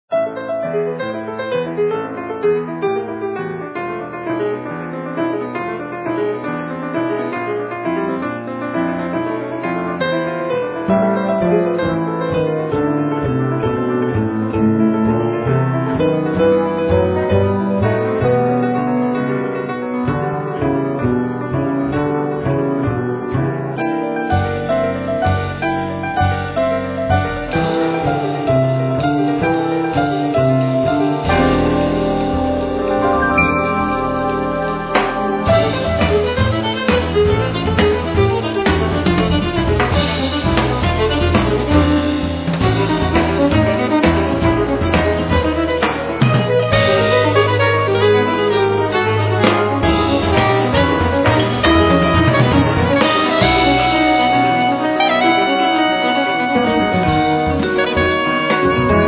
Piano,Keyboards
Alt & soprano sax
Guitars
Violin
Drums
Bass